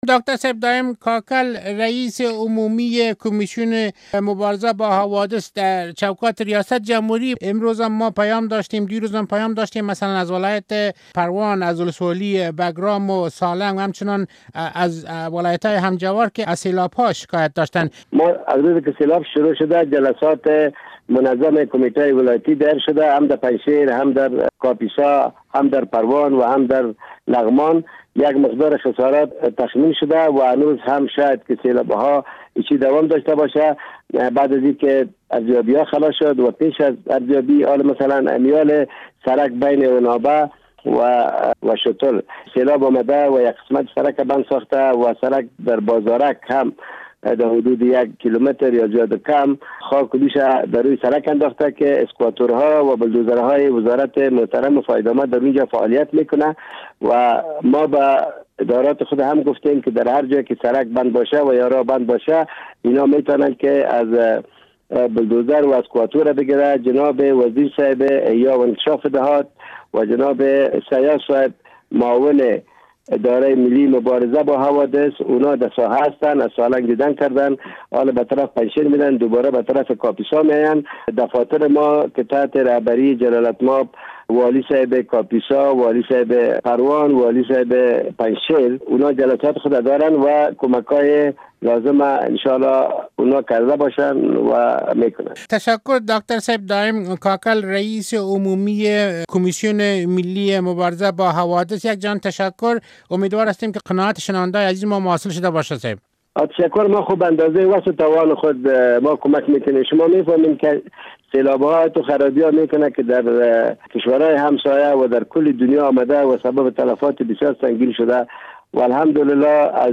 مصاحبه با دایم کاکر در مورد سیلاب های اخیر در ولایات شمالی افغانستان